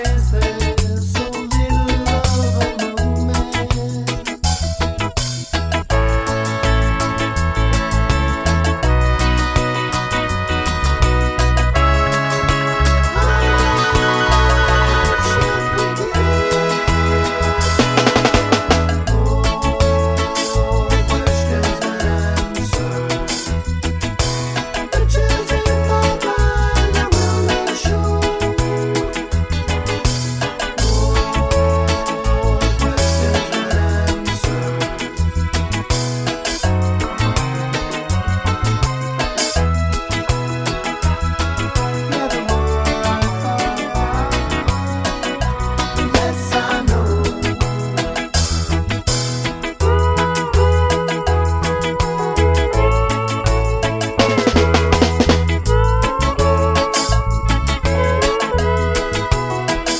No Backing Vocals Reggae 2:49 Buy £1.50